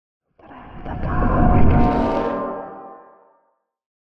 divination-magic-sign-rune-outro.ogg